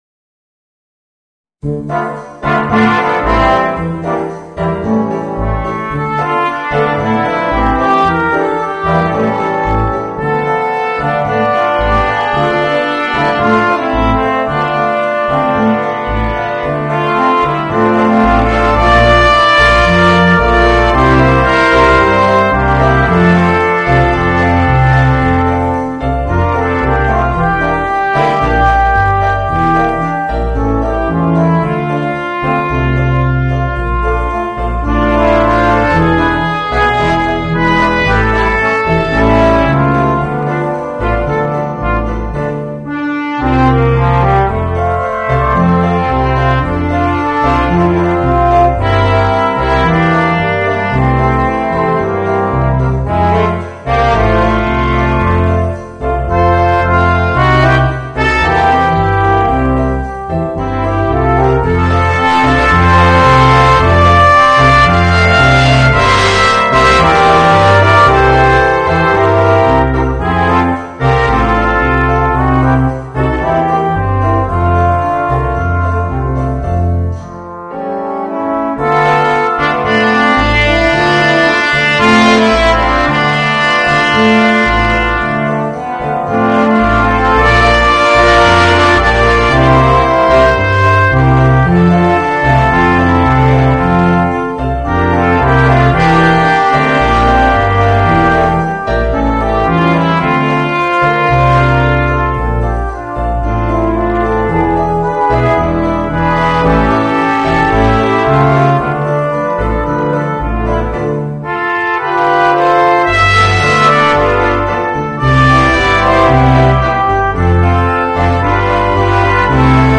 Voicing: 2 Trumpets, Horn, Trombone, Tuba and Piano